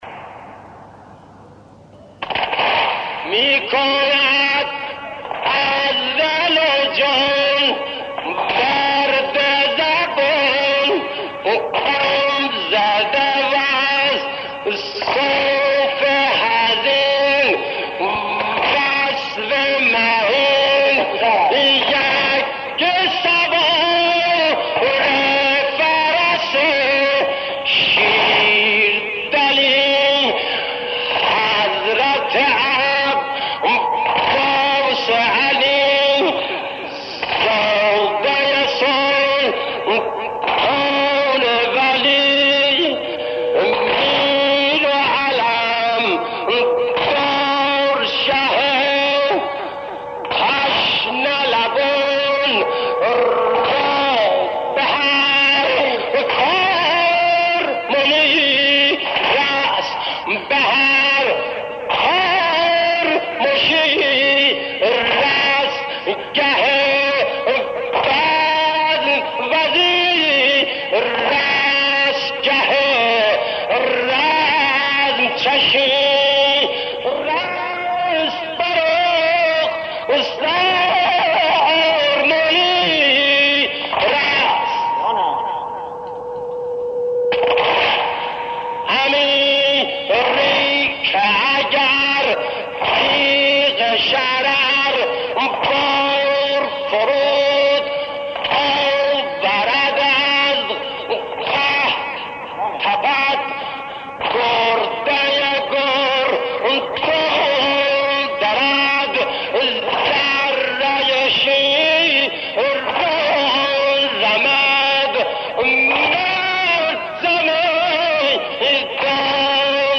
در دل شب‌های محرم، نغمه‌هایی بودند که با سوز دل مداحان قدیمی، راه دل را به کربلا باز می‌کردند.
بحرطویل خوانی